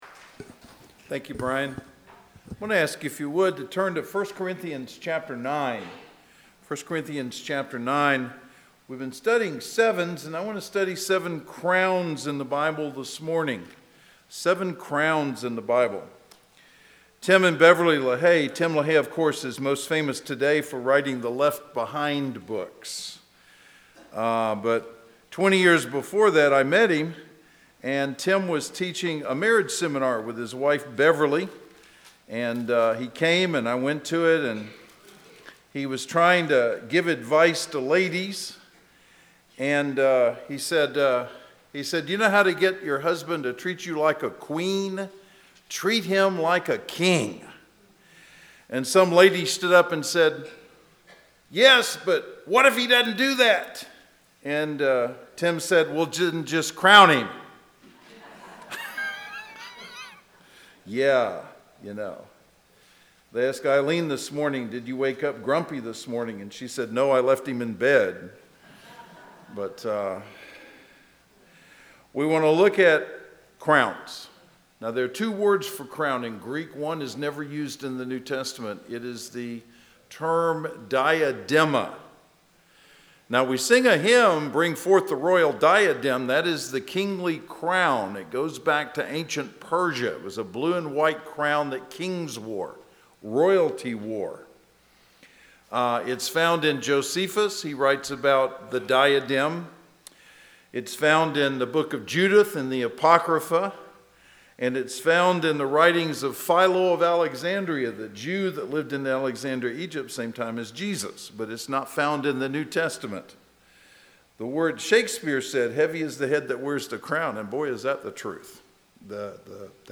Inman Park Baptist Church SERMONS